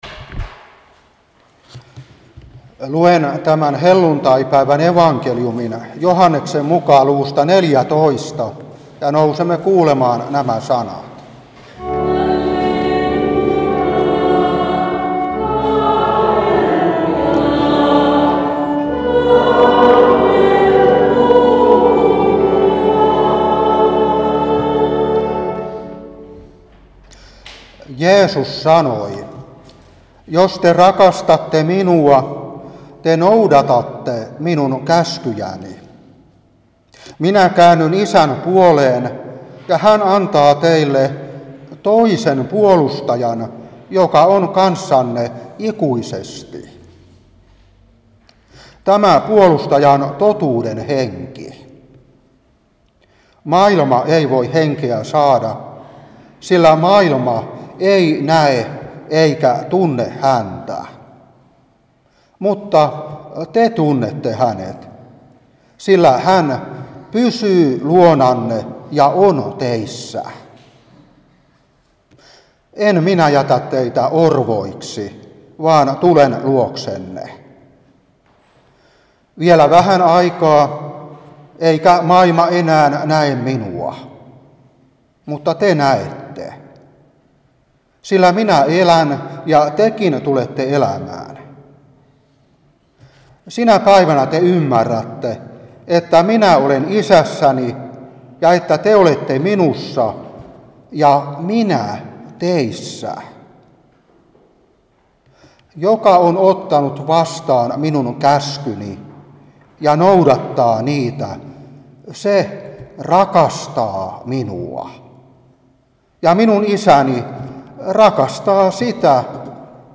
Saarna 2023-5.